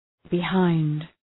Προφορά
{bı’haınd}